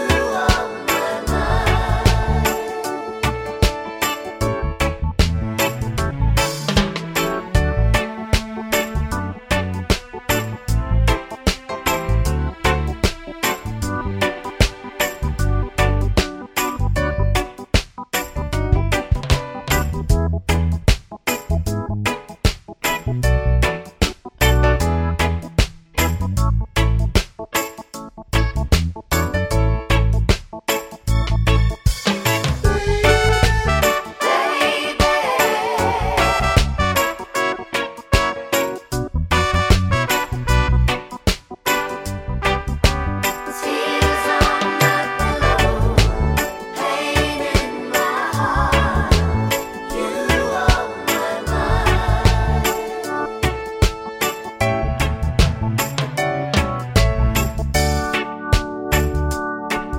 no Backing Vocals Reggae 3:19 Buy £1.50